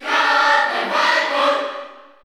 Crowd cheers (SSBU) You cannot overwrite this file.
Captain_Falcon_Cheer_Spanish_PAL_SSBU.ogg